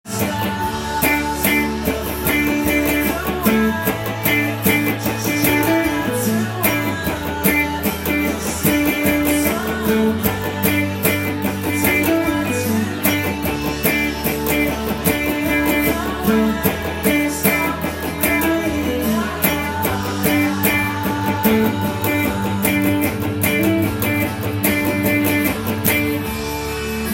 DメジャーになるのでDメジャーペンタトニックスケールを
音源にあわせて適当にカッティングしてみました